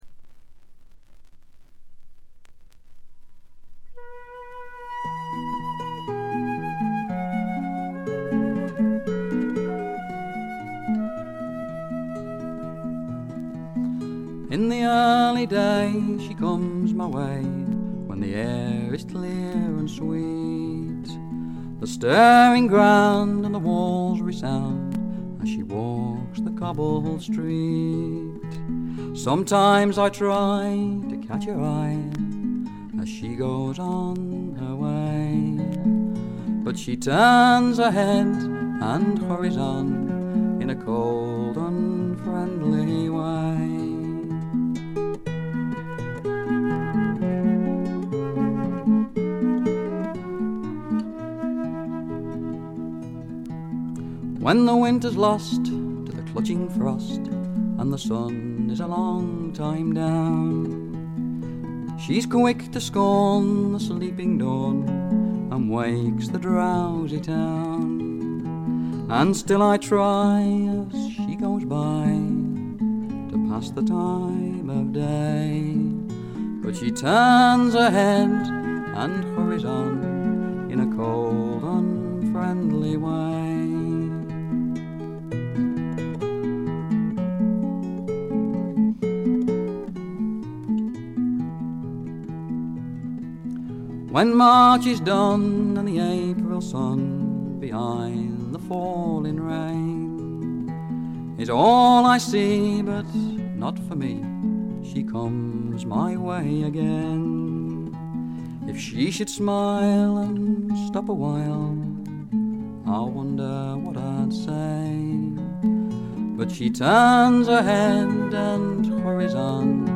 軽微なバックグラウンドノイズ、チリプチ。
試聴曲は現品からの取り込み音源です。
Flute